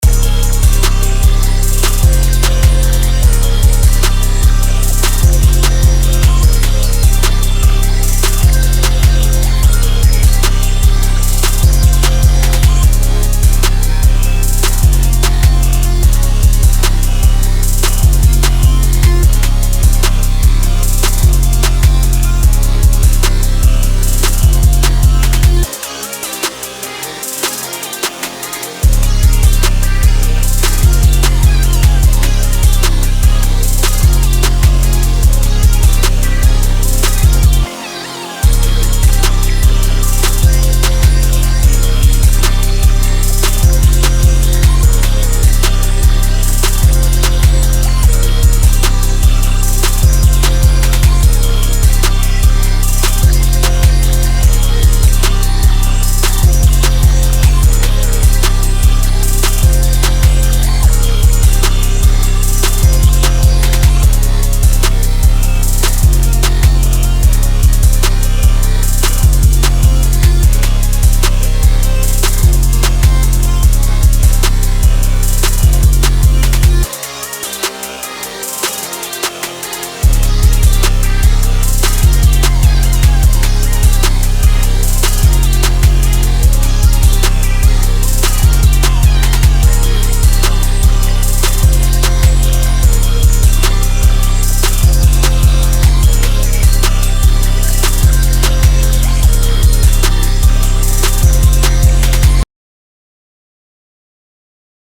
Минусовка песни